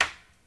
TC Clap Perc 04.wav